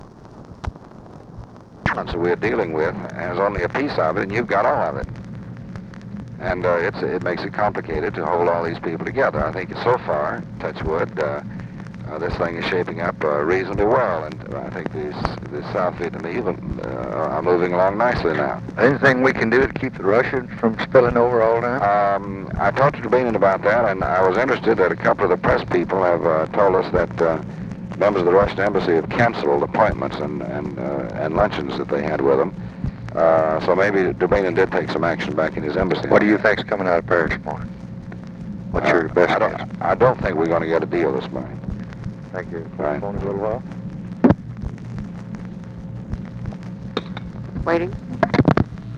Conversation with DEAN RUSK, October 24, 1968
Secret White House Tapes